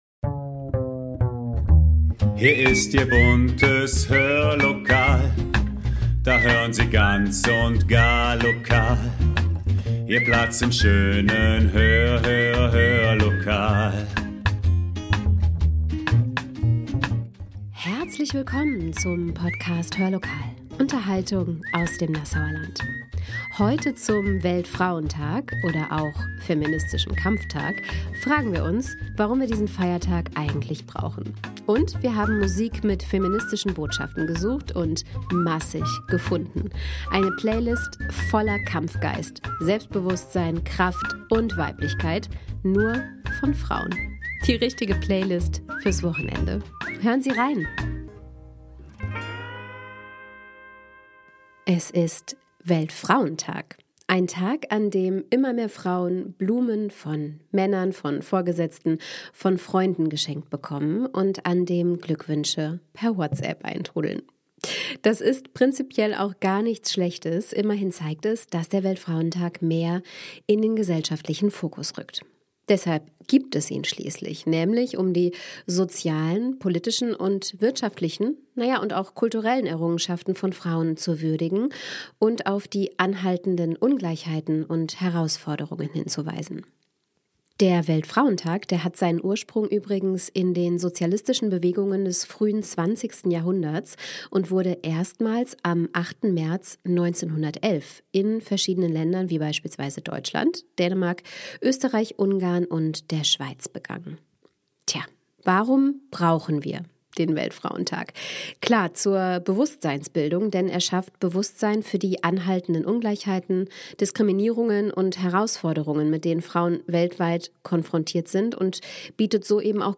Heute zum feministischen Kampftag oder auch Weltfrauentag fragen wir uns, ob und warum wir diesen Feiertag eigentlich brauchen und wir haben Musik mit feministischen Botschaften gesucht – und massig gefunden. Eine Playlist voller Kampfgeist, Selbstbewusstsein, Kraft und Weiblichkeit. Nur von Frauen!...